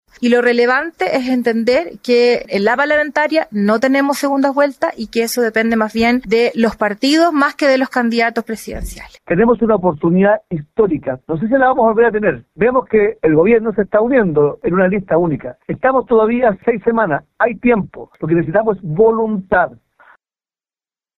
Tanto la vicepresidenta del gremialismo, María José Hoffmann, como el jefe de bancada de la tienda, Henry Leal, solicitaron que la oposición no cometa el mismo error de la elección de 2024, el que los habría llevado a perder municipios y gobiernos regionales que -de ir unidos- podrían estar en manos de la derecha.